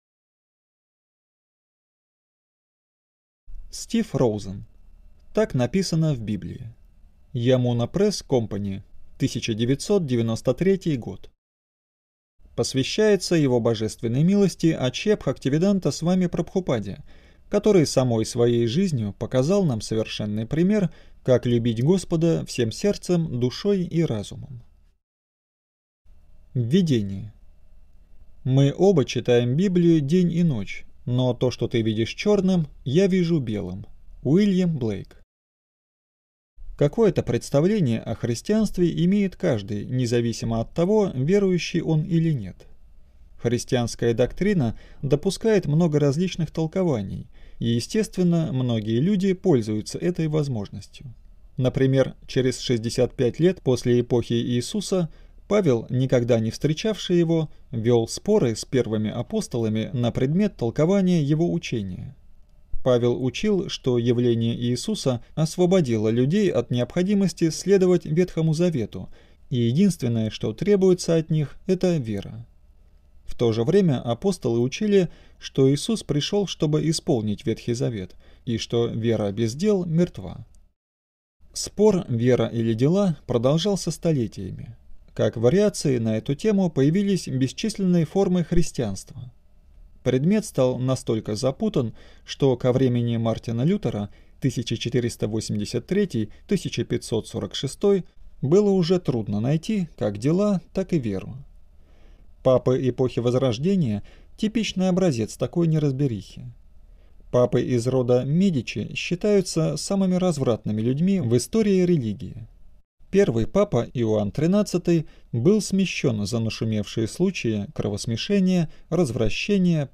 Аудиокнига "Так написано в Библии" – Лекции и книги Александра Хакимова